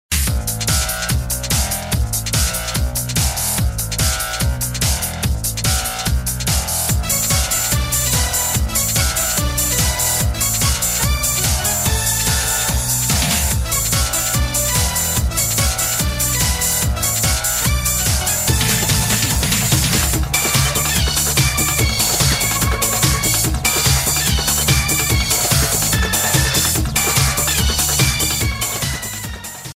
Fade-out added